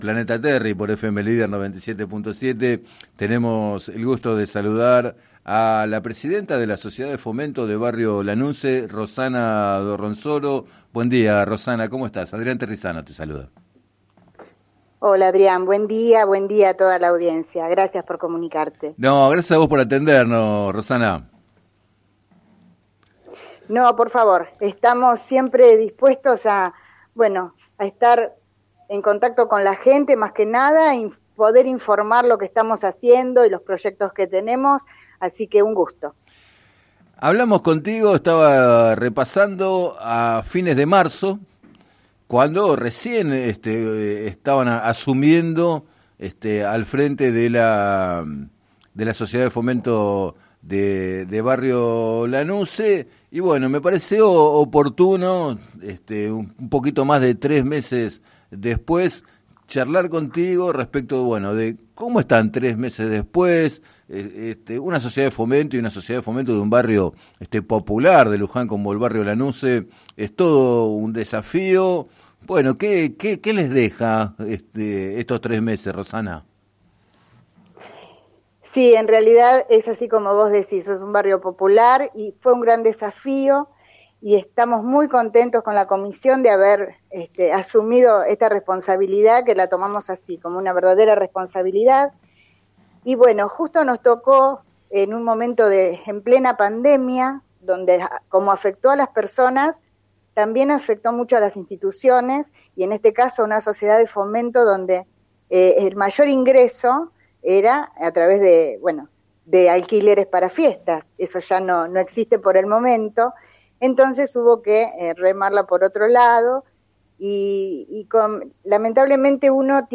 Entrevistada en el programa Planeta Terri de FM Líder 97.7